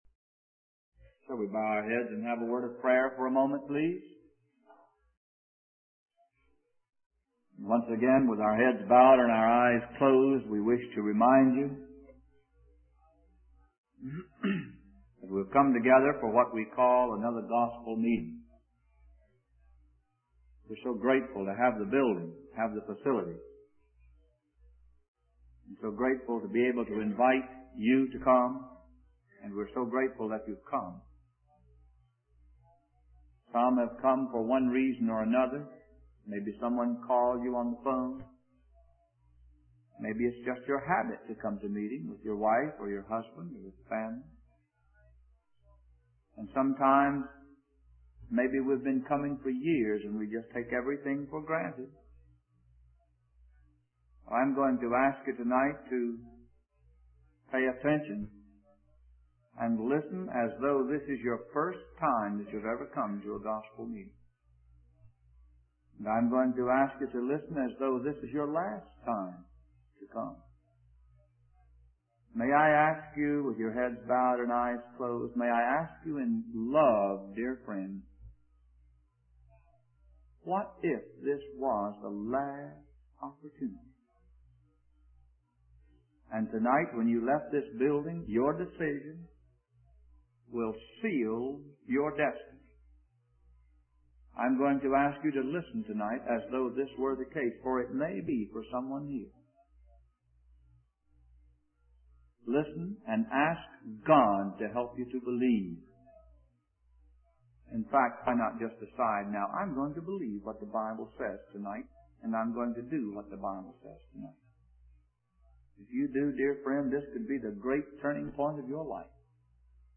In this sermon, the speaker shares a personal experience of visiting a friend in a penitentiary and going through multiple doors to enter the jail. He uses this analogy to explain that God has fully revealed himself to mankind through creation.